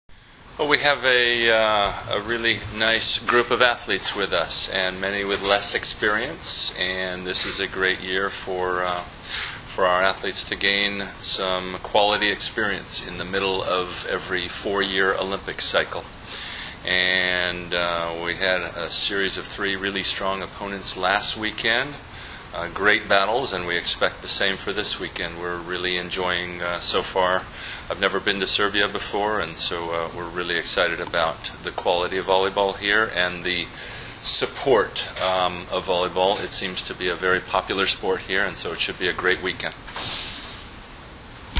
U beogradskom hotelu “M” danas je održana konferencija za novinare povodom predstojećeg turnira F grupe II vikenda XXI Gran Prija 2013.
IZJAVA KARČA KIRALJA, TRENERA SAD